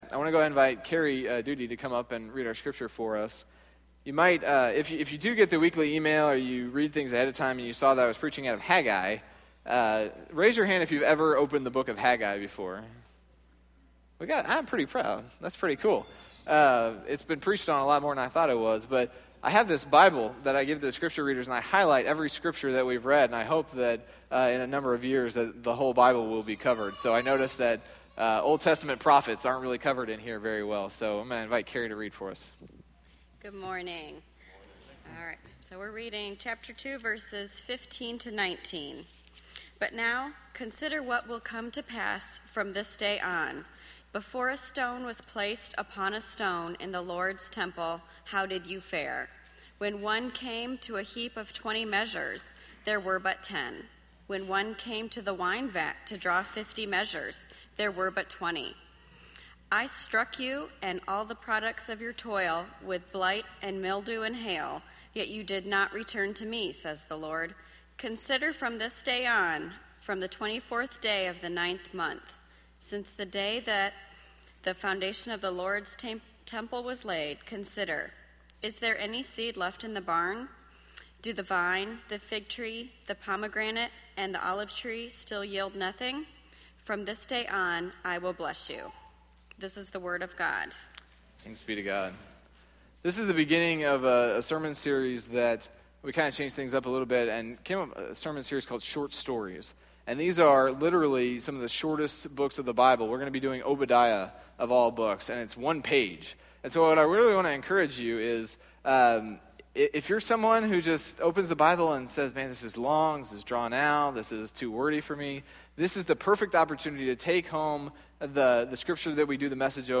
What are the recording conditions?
Recorded at Stonebridge United Methodist Church in McKinney, Texas.